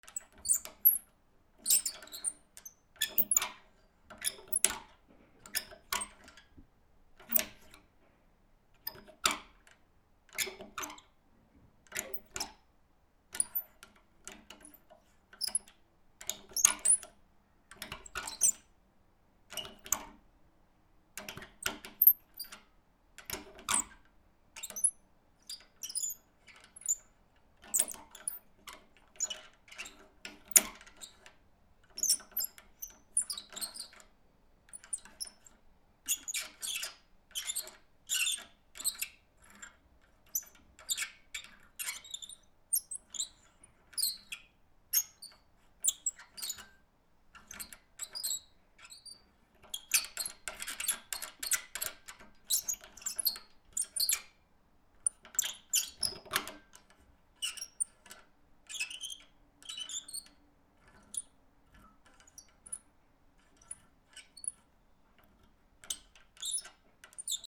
/ K｜フォーリー(開閉) / K51 ｜ドア－きしみ
金具 キュッキュッ(小さいきしみのような音)